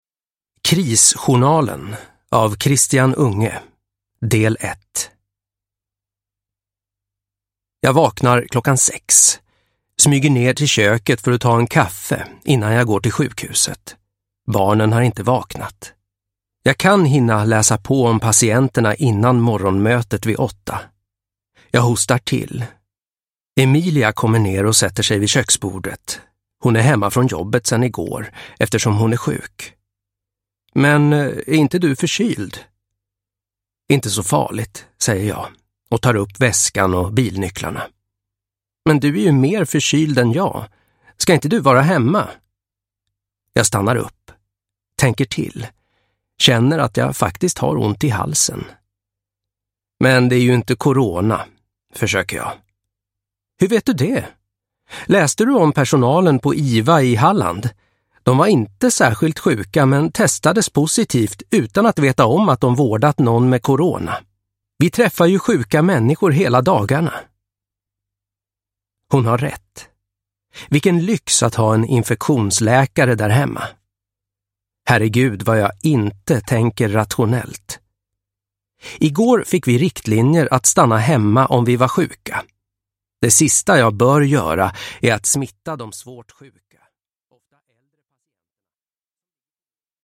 Krisjournalen - 1 - Oron sprider sig – Ljudbok – Laddas ner